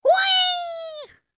Tono SMS de pájaro enojado gratuito para teléfono móvil.
Categoría Notificaciones